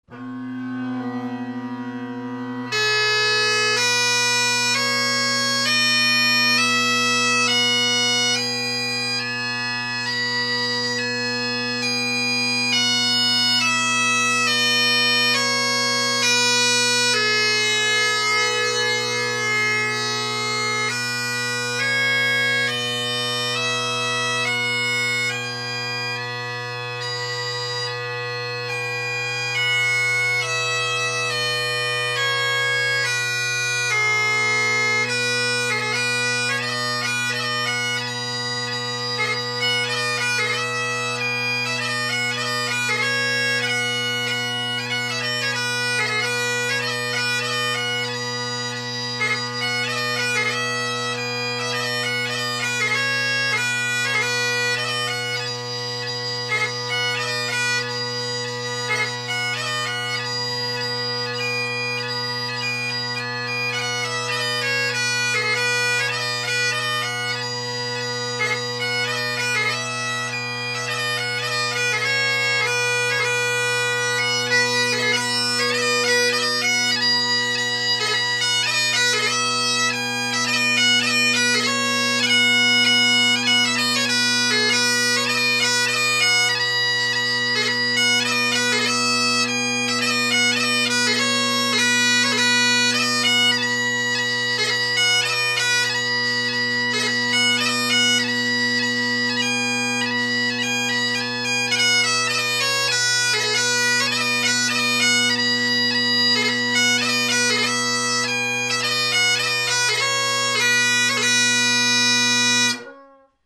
Great Highland Bagpipe Solo, Reviews
This chanter is louder than any other I’ve recorded, so pay attention to your speaker/headphone volume.
First scale is facing the mic, second scale facing away, first STB facing away, second STB facing the mic again. Big volume changes!!! This reed and chanter combo is loud.
The pipes played are my Colin Kyo bagpipe with Ezee tenors and short inverted Ezee bass.